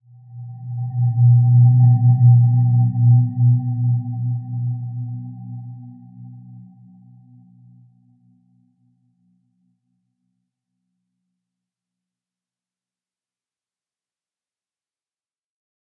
Dreamy-Fifths-B2-mf.wav